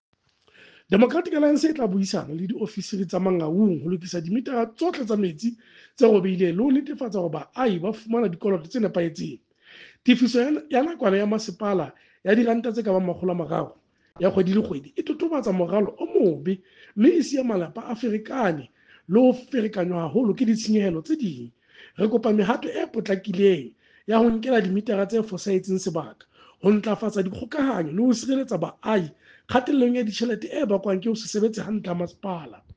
Sesotho soundbite by Cllr Kabelo Moreeng.
Mangaung-fails-to-address-broken-water-meters-SOTHO-.mp3